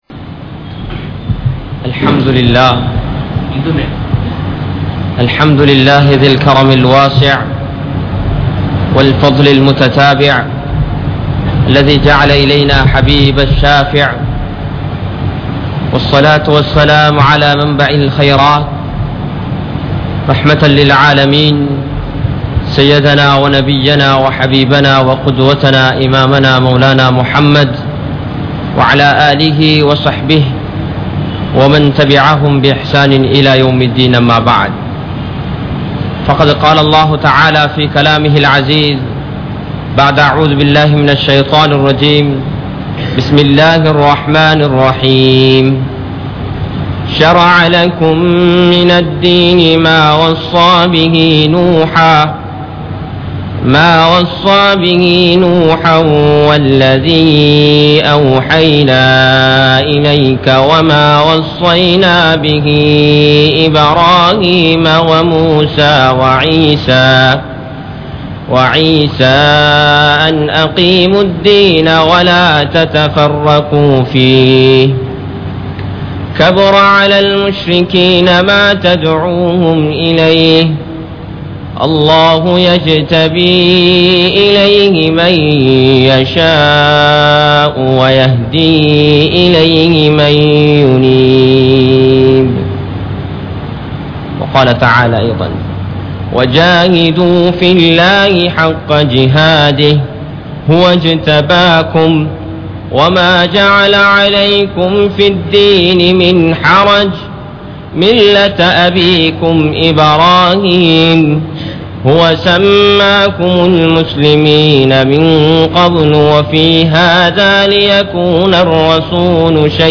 Naattin Thatpoathaya Nilamai (நாட்டின் தற்போதைய நிலைமை) | Audio Bayans | All Ceylon Muslim Youth Community | Addalaichenai
Kollupitty Jumua Masjith